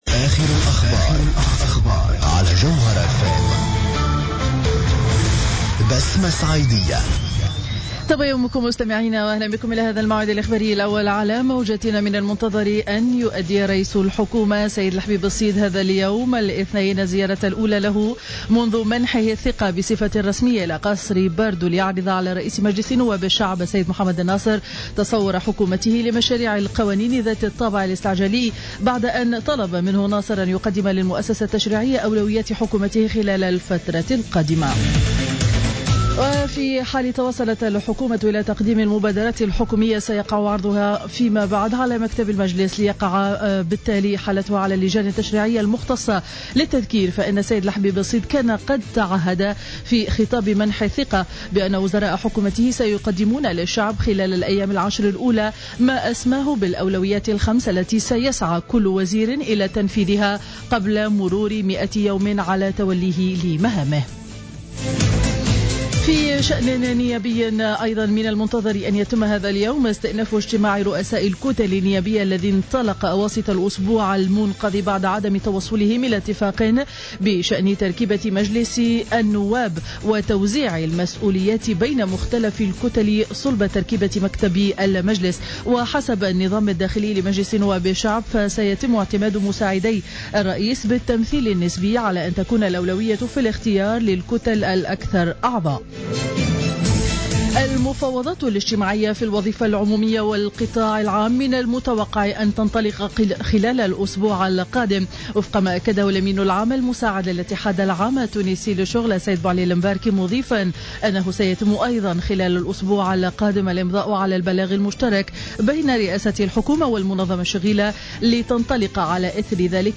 نشرة اخبار السابعة صباحا ليوم الإثنين 16 فيفري 2015